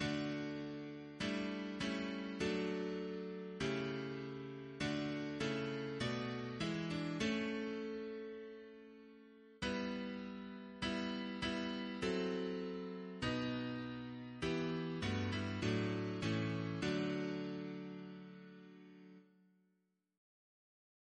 Double chant in D Composer: Sir John Stainer (1840-1901), Organist of St. Paul's Cathedral Note: after Beethoven Reference psalters: ACB: 331; CWP: 78; RSCM: 125